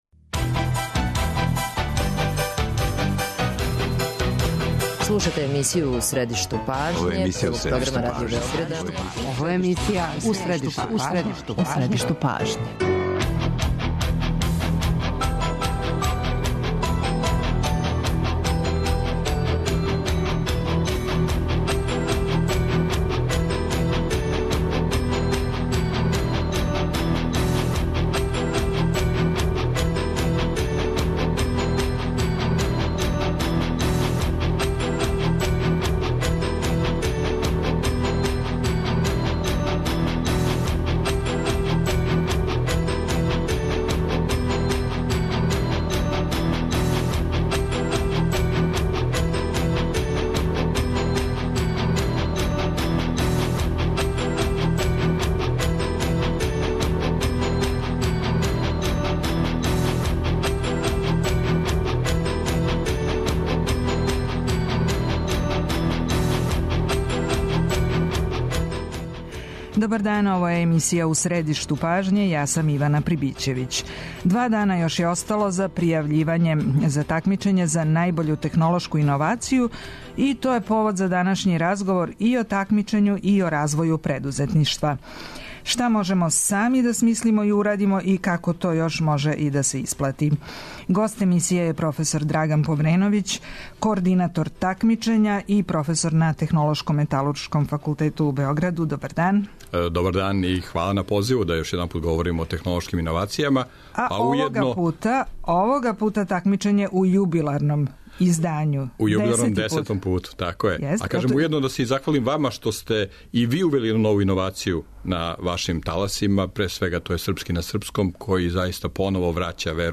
Путем телефона у разговор ће се укључити др Александар Белић, државни секретар у Министарству просвете, науке и технолошког развоја и Биљана Војводић, помоћница министра за технологију Републике Српске.